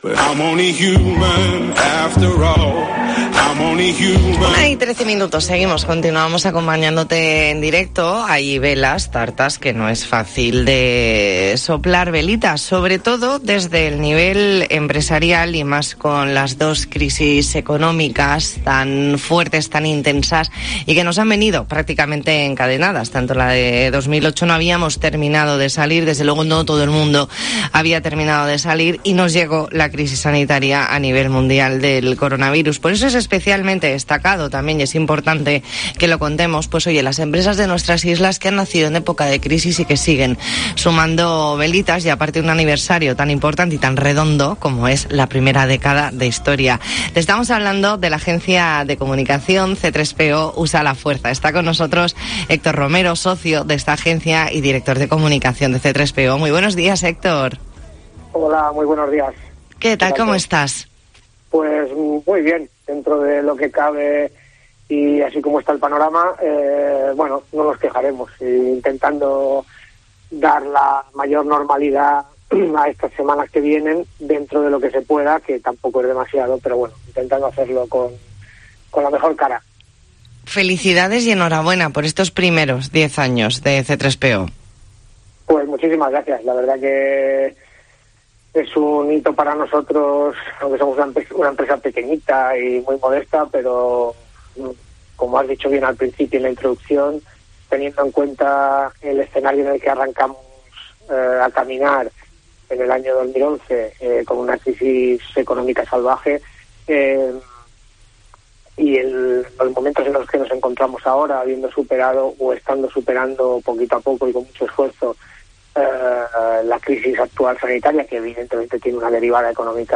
Entrevista en La Mañana en COPE Más Mallorca, martes 21 de diciembre de 2021.